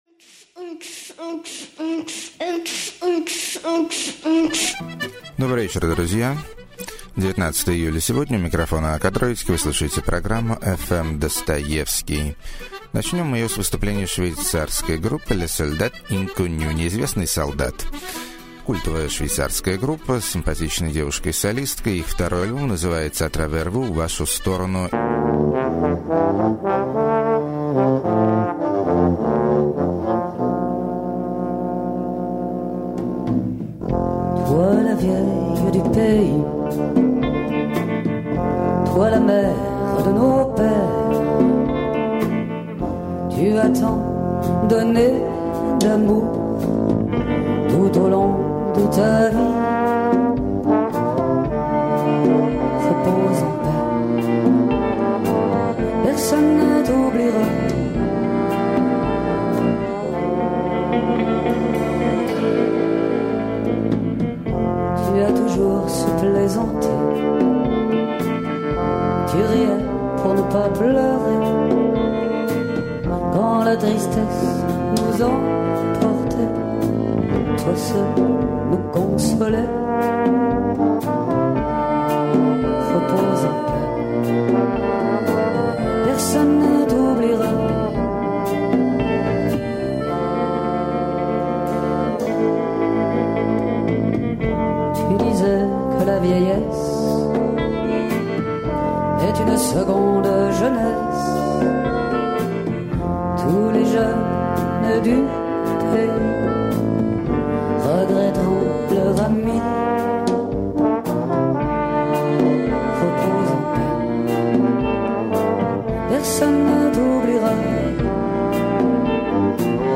Конго + Куба = Музыка Для Танцев До Утра.
Стиль Morna В Чистом Виде.
Густой Стариковский Американский Психоделик.